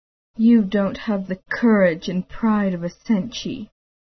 Low range